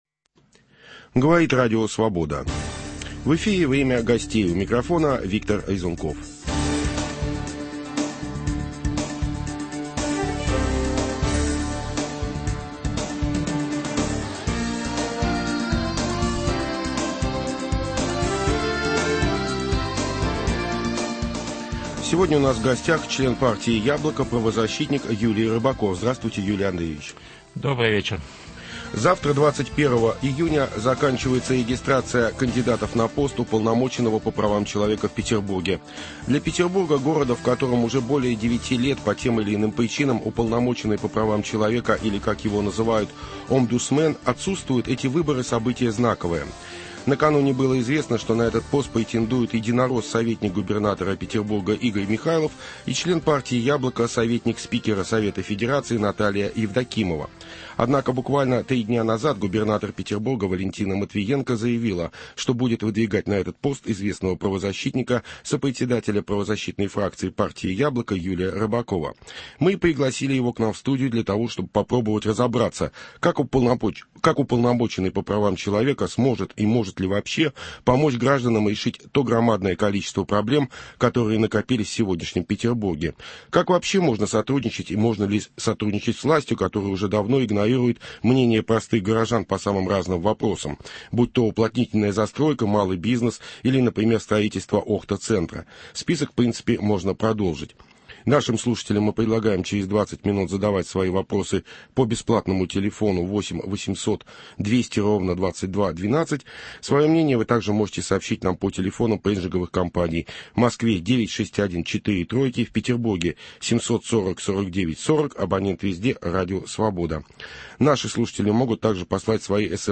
Что может и должен сделать обдусмен в сегодняшнем Петербурге? Беседа с Юлием Рыбаковым.